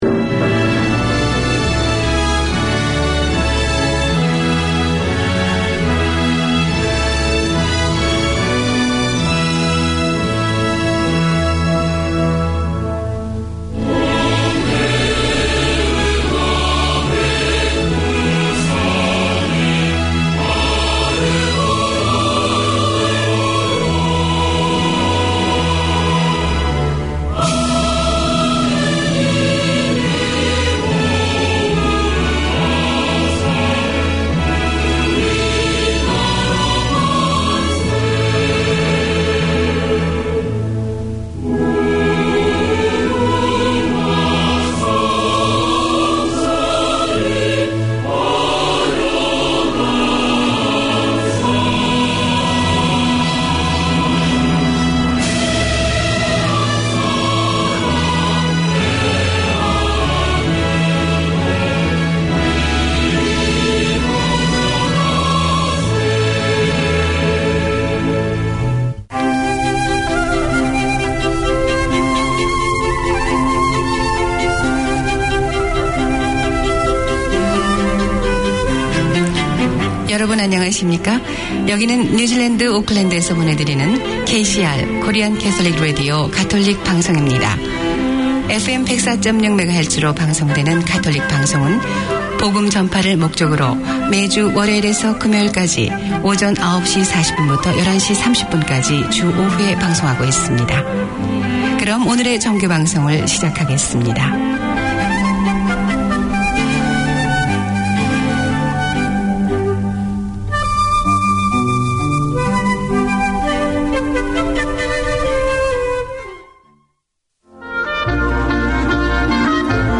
Radio made by over 100 Aucklanders addressing the diverse cultures and interests in 35 languages.
Community magazine